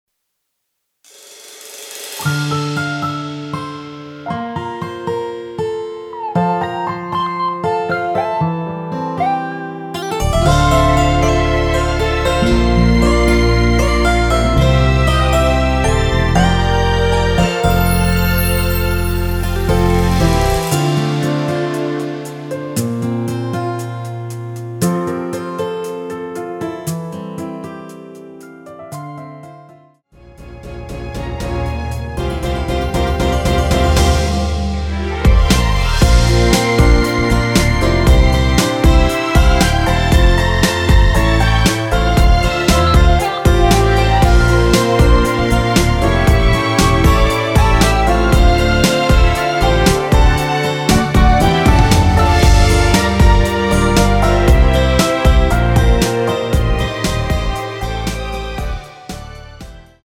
여성분이 부르실수 있는 키의 MR입니다.
원키에서(+5)올린 MR입니다.(미리듣기 참조)
Dm
앞부분30초, 뒷부분30초씩 편집해서 올려 드리고 있습니다.
중간에 음이 끈어지고 다시 나오는 이유는